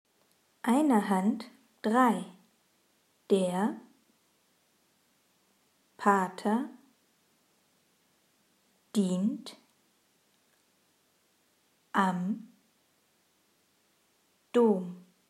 Satz 1 Langsam